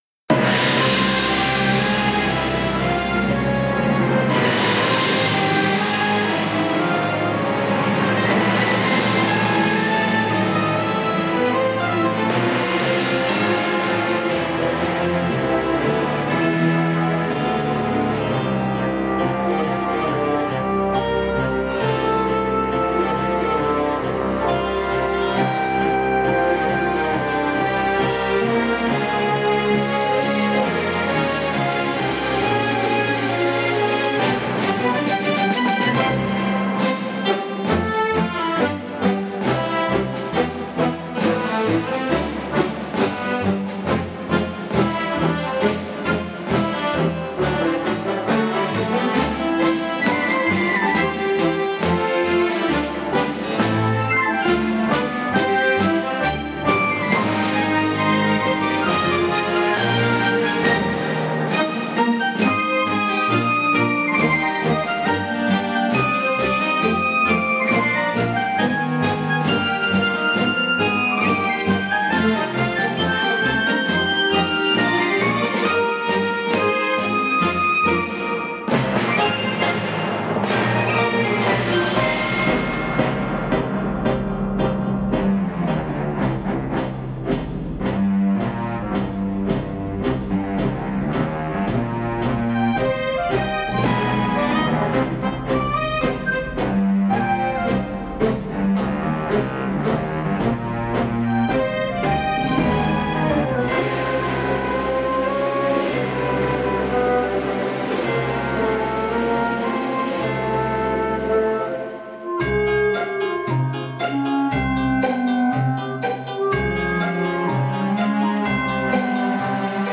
orchestration added 2/7/20
20CC_12.1-CaesarsProsecution_NoBiii-orch.mp3